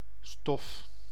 Ääntäminen
IPA: [stɔf]